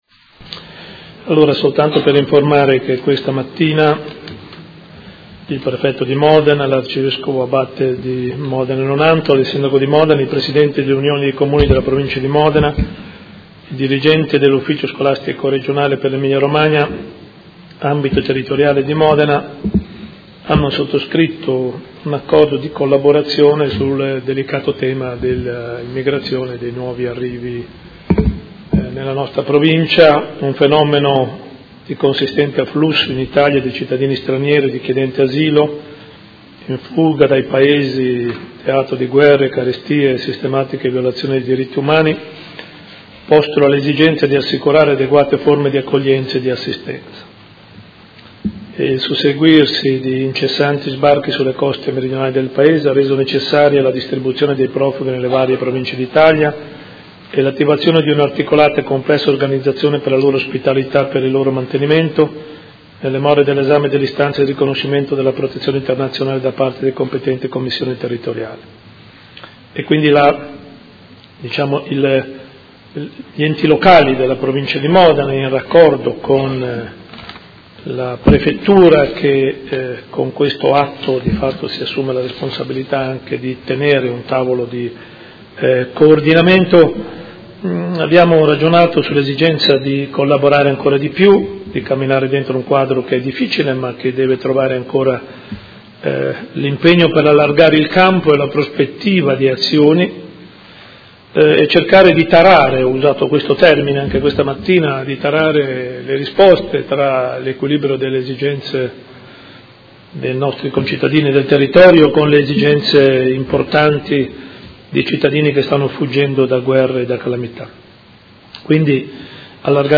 Seduta del 20/10/2016. Comunicazione su rifugiati
Sindaco